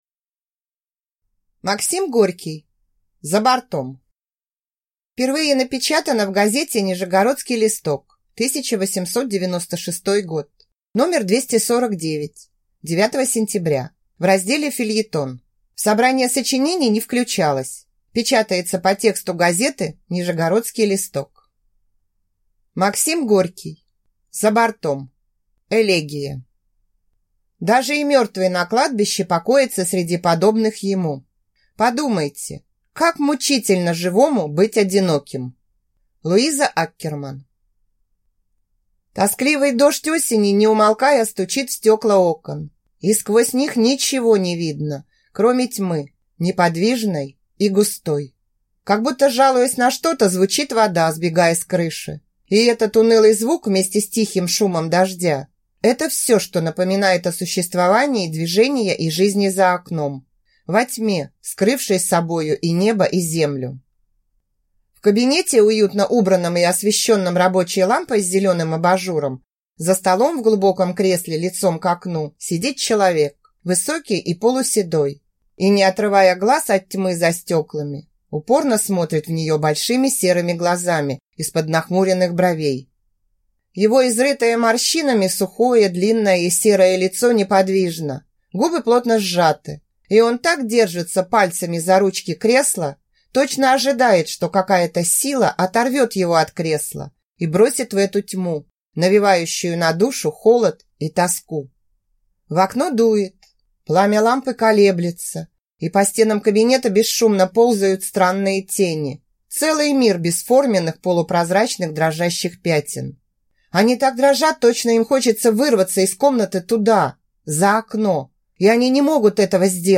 Аудиокнига За бортом | Библиотека аудиокниг